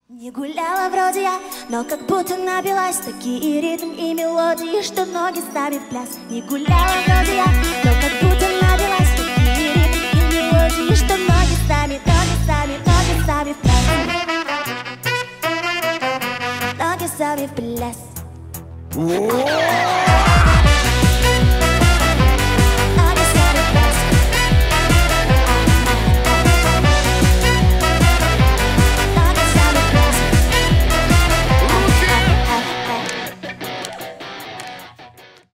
Рок Металл # Танцевальные